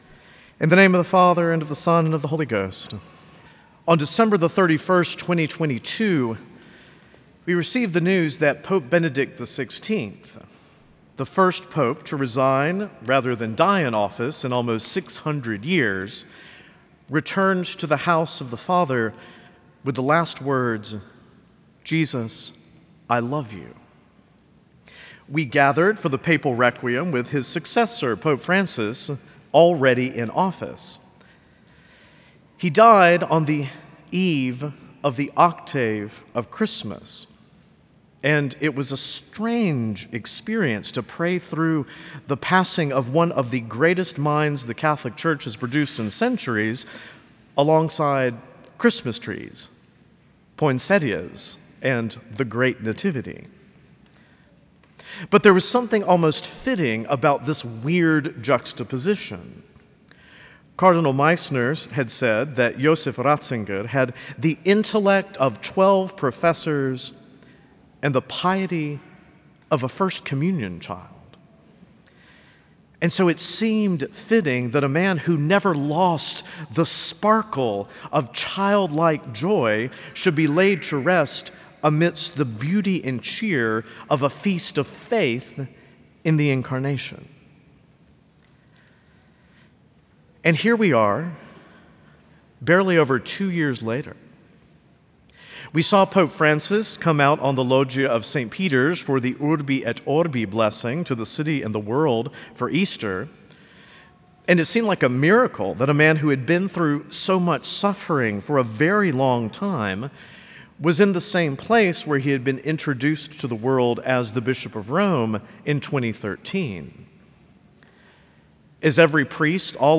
Homilies - Prince of Peace Catholic Church & School